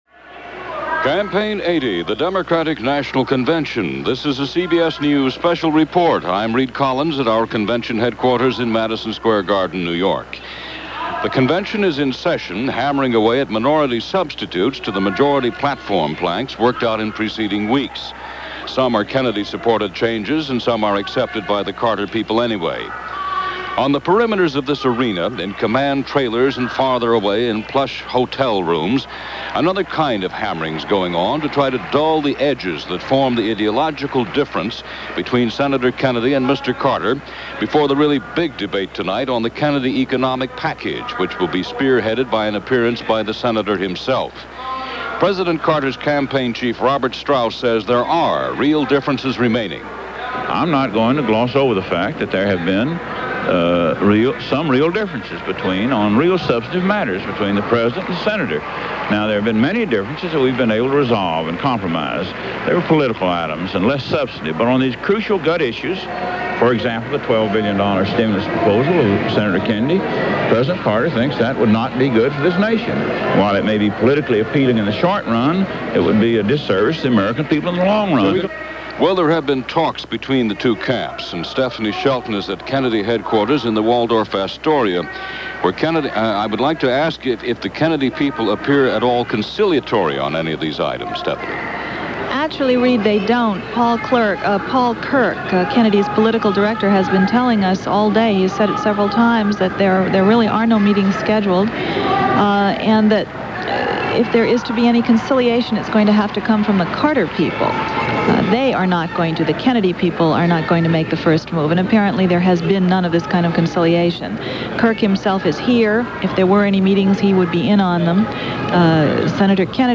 And so this day was about speeches and pledges and a much-anticipated debate of the Carter Health Plan or the Kennedy Health Plan and where was the middle ground. Peppered throughout the day were reports from the floor of the convention at Madison Square Garden – and here are a few of those reports, along with news of the day from CBS Radio News on August 10, 1980.